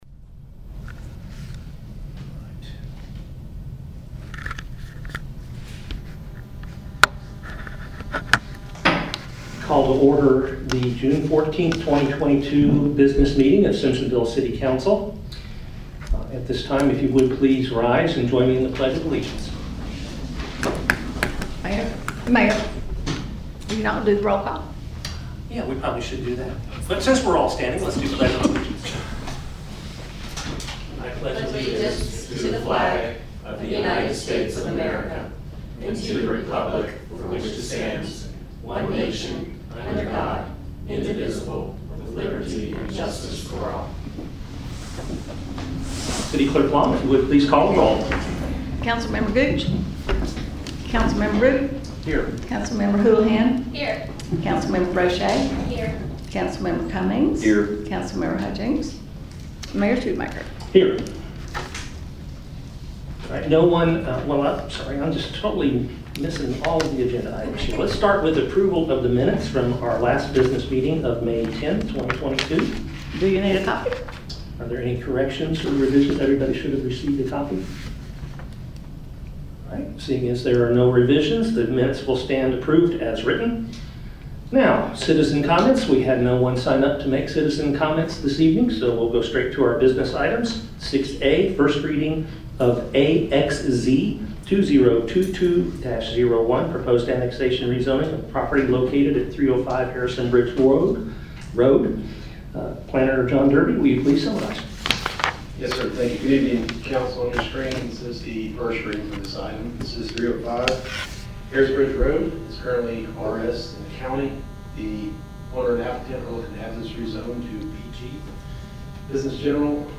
City Council Business Meeting
Simpsonville City Council will hold a regularly scheduled business meeting June 14th at 6:30 p.m. in Council Chambers at City Hall.